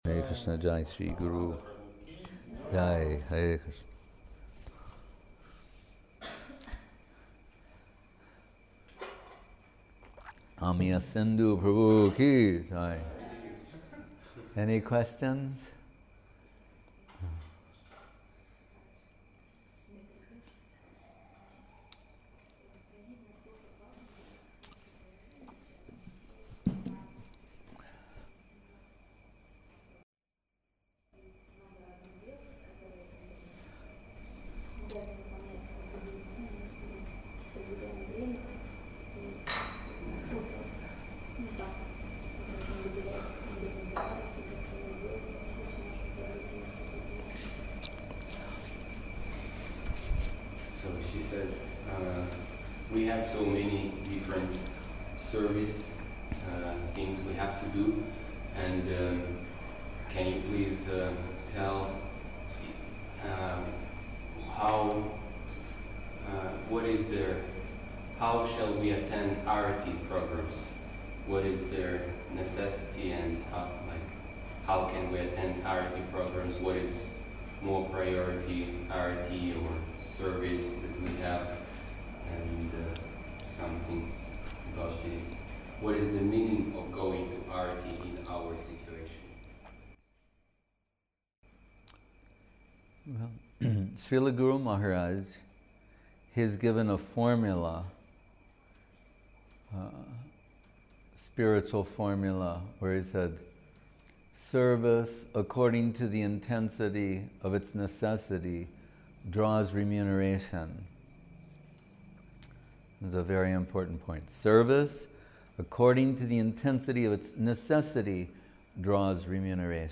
Place: Gupta Govardhan Chiang Mai